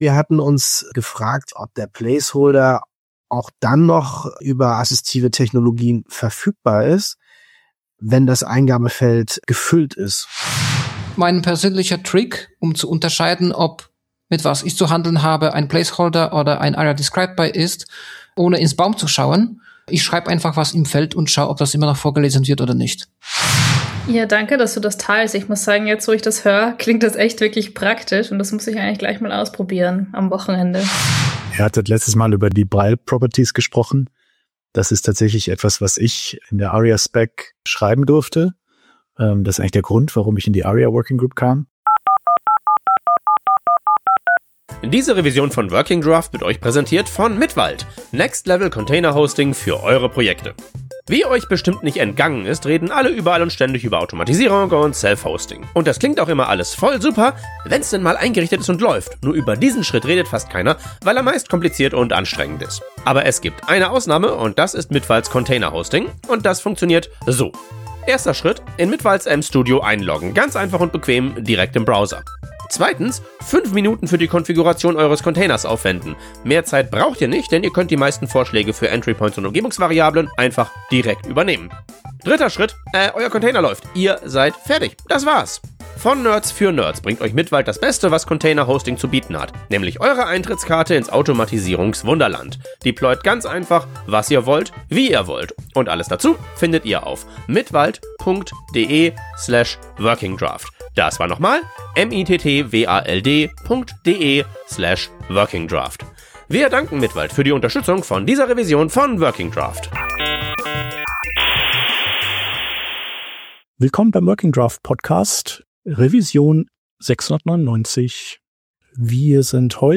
In dieser Folge sprechen wir zu zweit über unsere Eindrücke rund um den Government Site Builder (GSB) – ausgelöst durch unseren Besuch auf der T3CON in Düsseldorf.
Herausgekommen ist stattdessen eine kurze, leicht rantige Bestandsaufnahme darüber, wie schwer es ist, überhaupt belastbare Informationen oder Gesprächspartner:innen zum GSB zu finden.